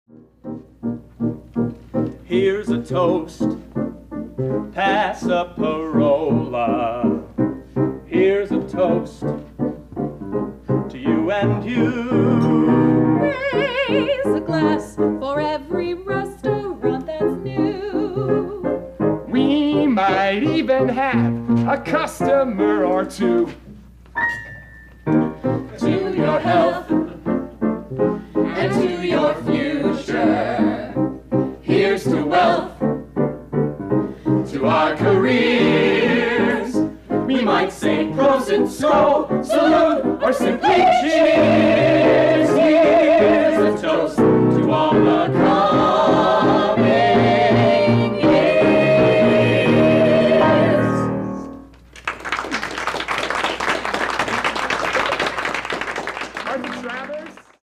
Song Demo Performance Credits
"Here's a Toast": the staff and management of Bella Sicilia